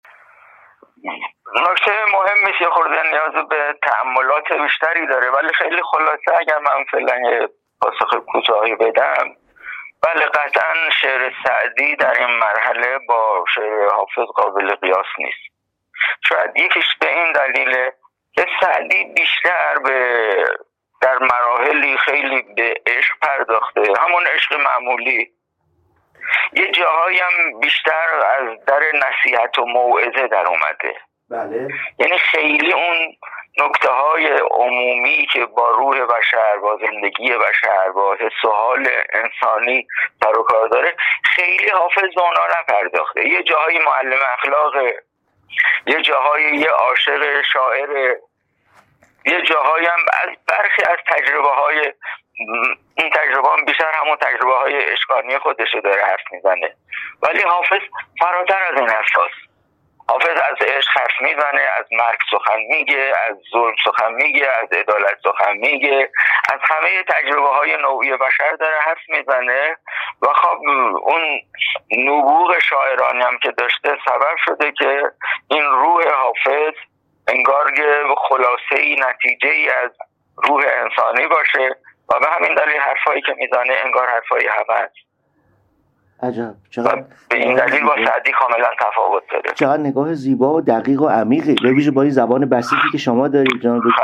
بربلندای امواج تلفن